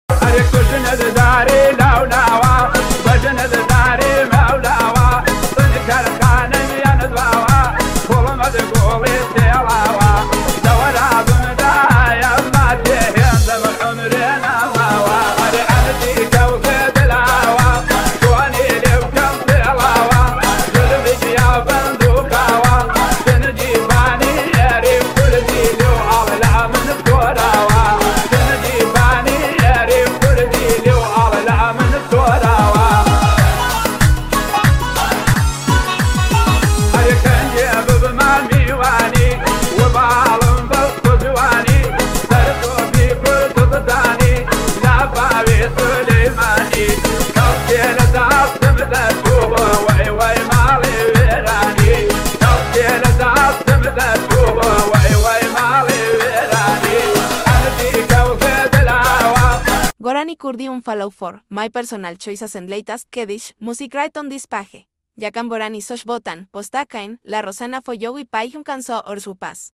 MG T60 pickup sound effects free download